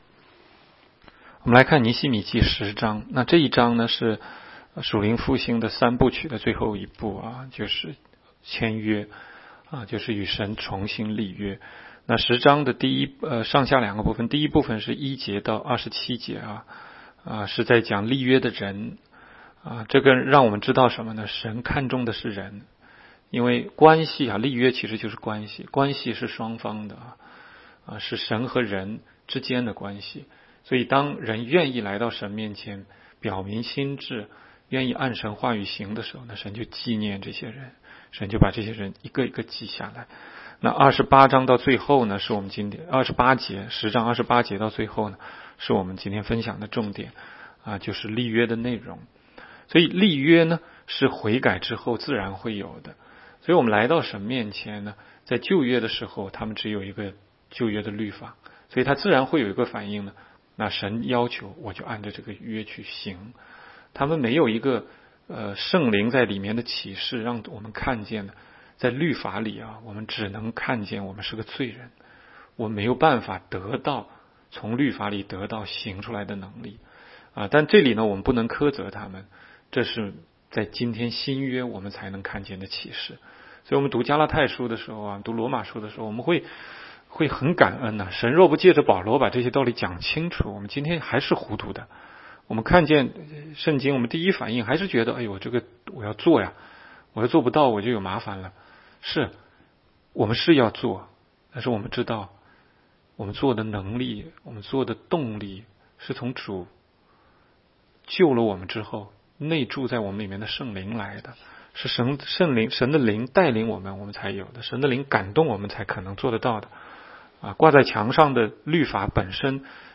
16街讲道录音 - 每日读经-《尼希米记》10章